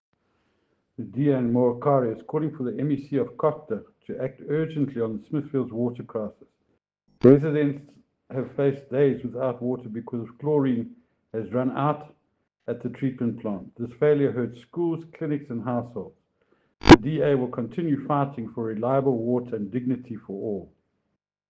English soundbite by Cllr Ian Riddle,